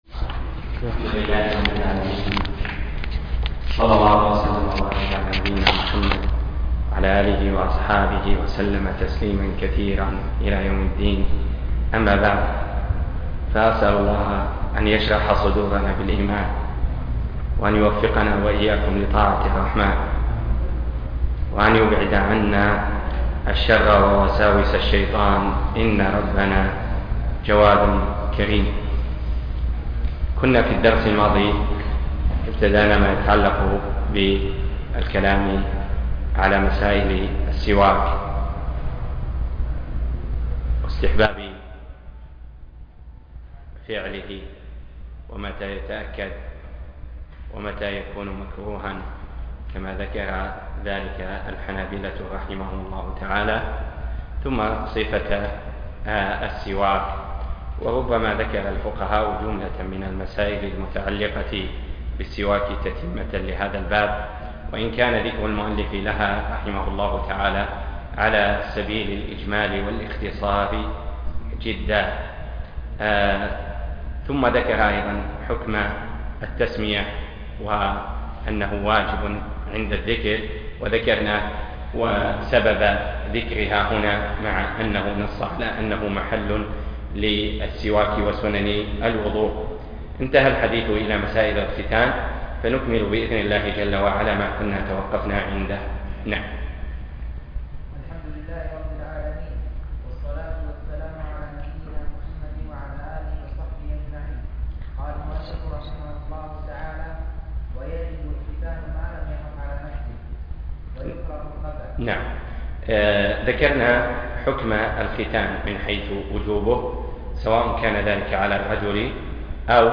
زاد المستقنع - باب السواك - باب فروض الوضوء - الدرس (7)